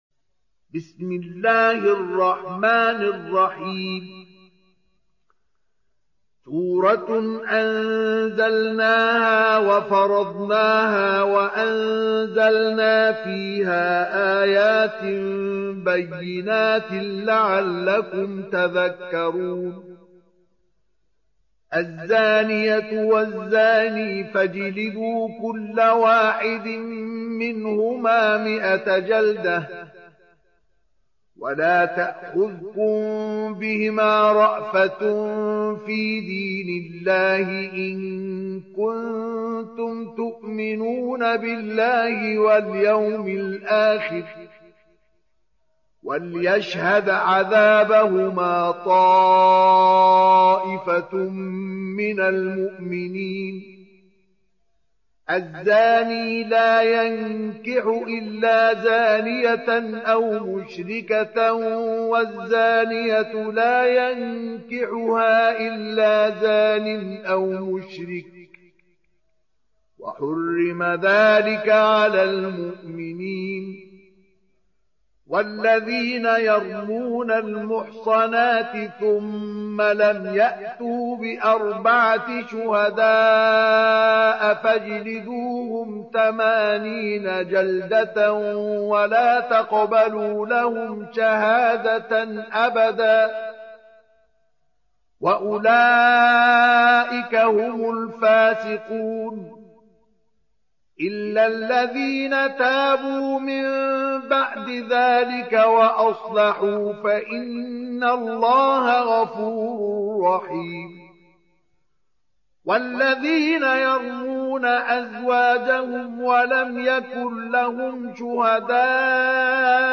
Surah An-N�r سورة النّور Audio Quran Tarteel Recitation
Surah Sequence تتابع السورة Download Surah حمّل السورة Reciting Murattalah Audio for 24.